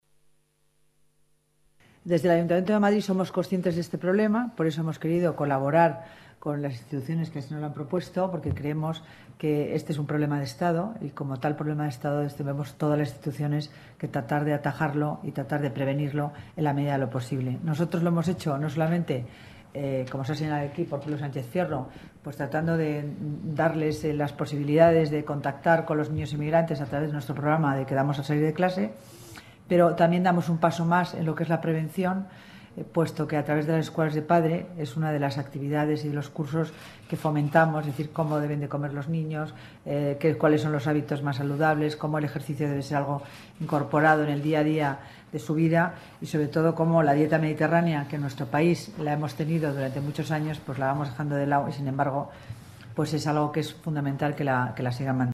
Nueva ventana:Declaraciones de Concepción Dancausa, delegada de Familia y Servicios Sociales